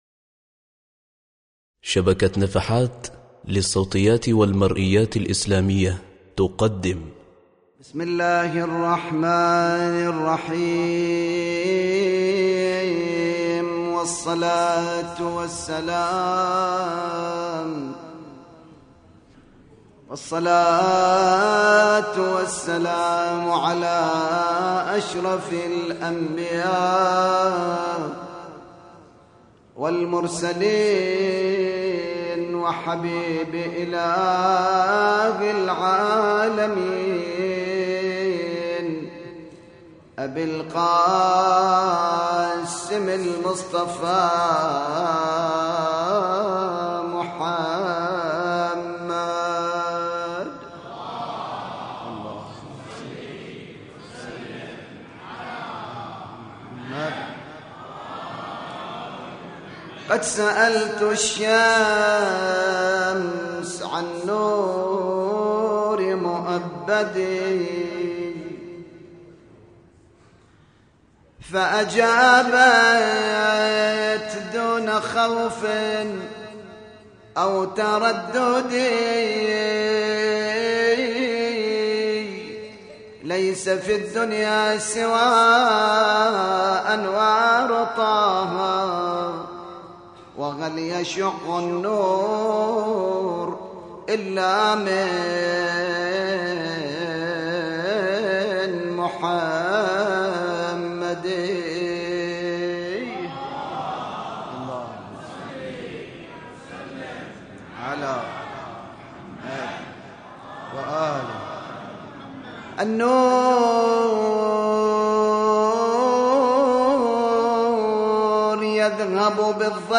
مولد أبو الفضل العباس ع -1434هـ – مسجد العباس ع بالربيعية